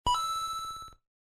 notificacao.mp3